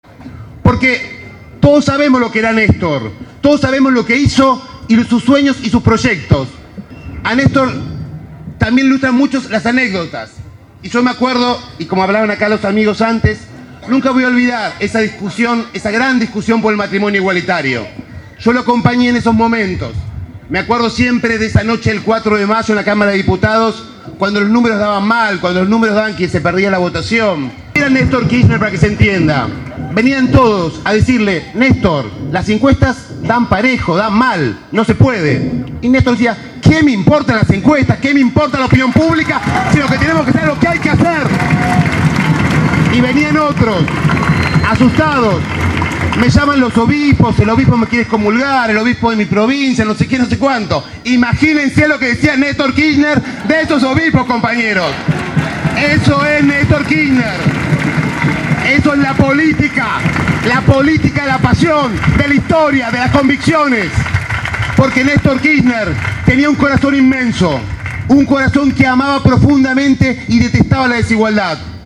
Al cumplirse un mes de su fallecimiento, La Cámpora organizó en la ciudad de La Plata un acto central en homenaje al ex Presidente.
También podemos escuchar a Juan Manuel Abal Medina, orador central, en tres fragmentos de su discurso